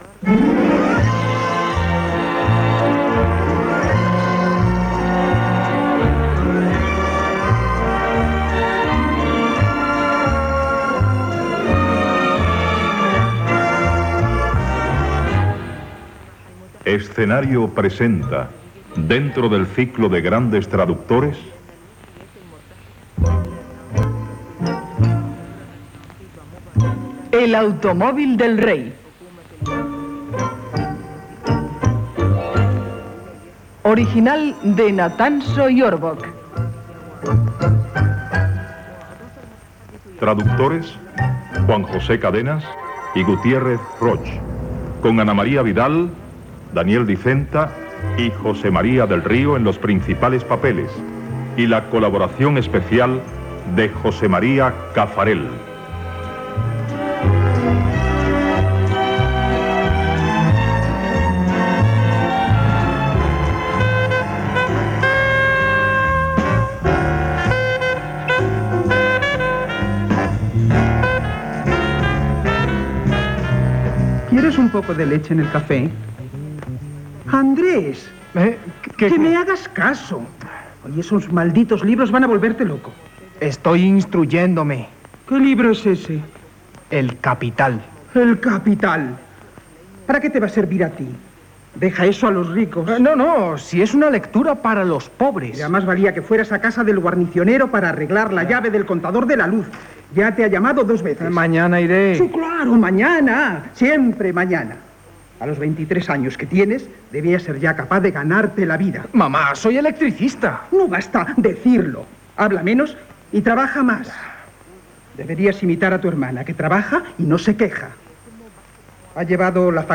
Música i veu amb títol del programa que s'integra dins del cicle de grans traductors.
Música i comença l'obra.
Ficció